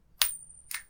Coin Flip - 1
bet buy coin coins ding drop dropping falling sound effect free sound royalty free Sound Effects